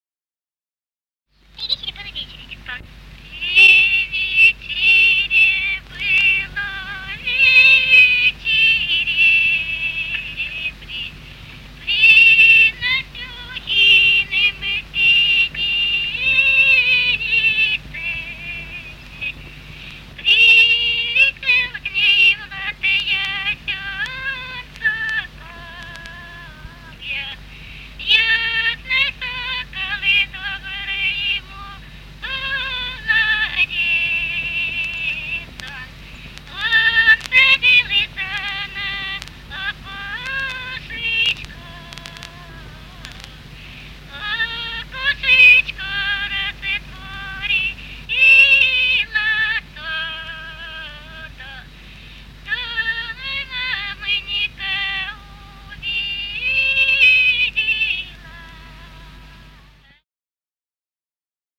Русские народные песни Владимирской области 21. При вечере было, вечере (свадебная) с. Коровники Суздальского района Владимирской области.